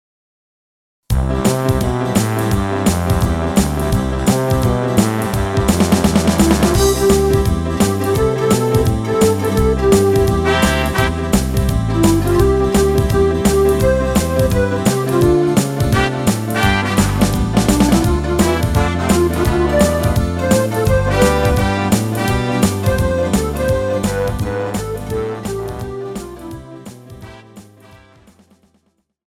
KARAOKE/FORMÁT:
Žánr: Evergreen